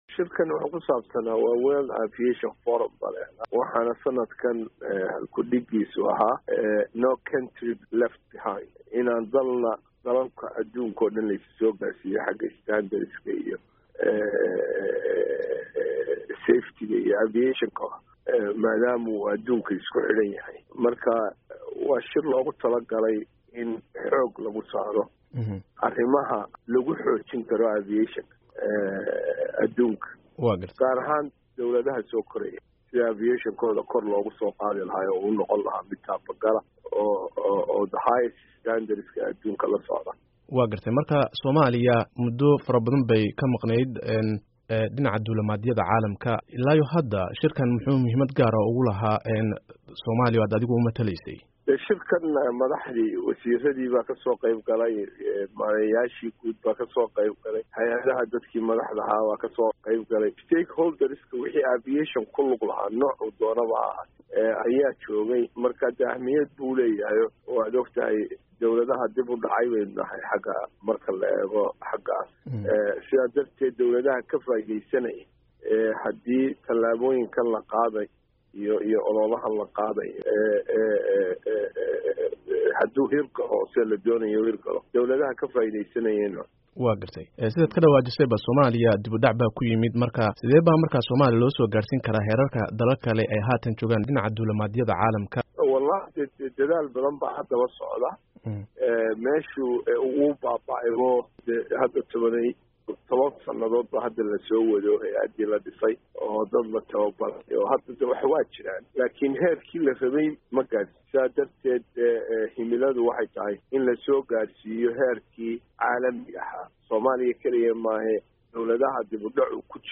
Wareysi: Wasiirka Duulista Soomaaliya